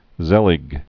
(zĕlĭg)